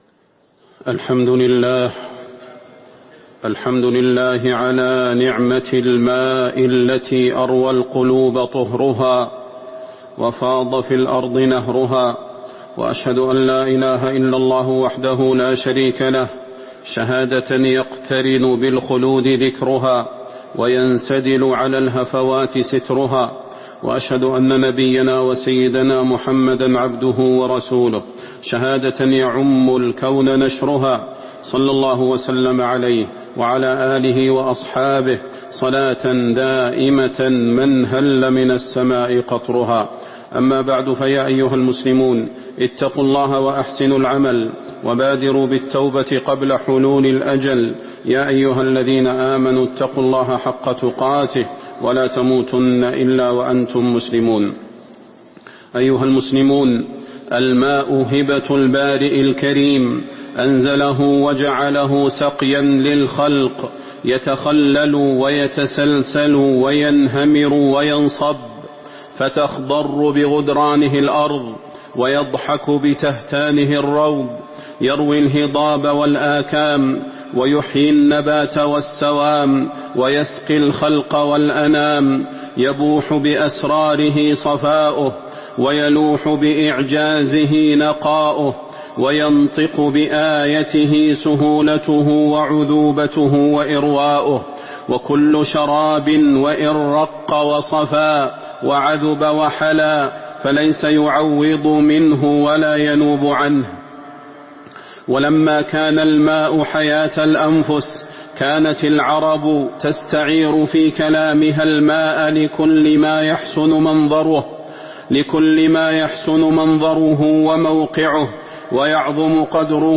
تاريخ النشر ٩ صفر ١٤٤٥ هـ المكان: المسجد النبوي الشيخ: فضيلة الشيخ د. صلاح بن محمد البدير فضيلة الشيخ د. صلاح بن محمد البدير فضل سقيا الماء